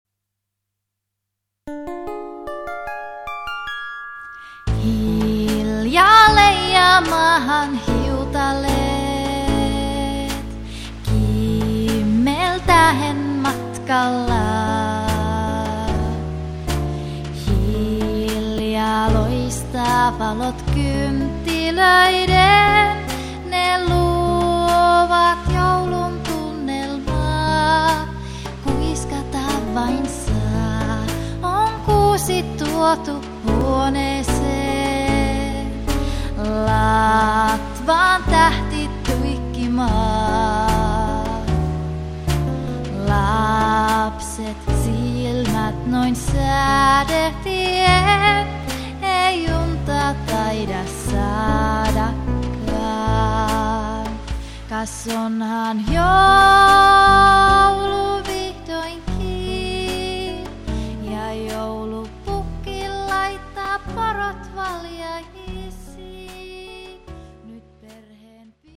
laulut
kitarat
Äänitys on tehty kotistudiossa Kemissä.
Muut instrumentit on ohjelmoitu MIDI-tekniikalla.